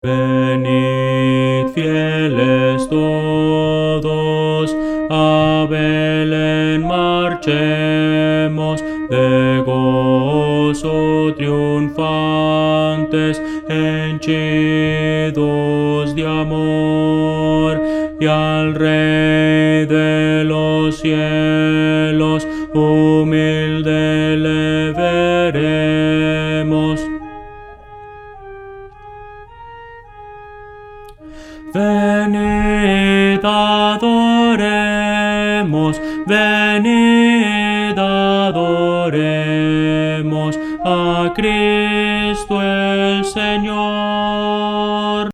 Voces para coro
Contralto – Descargar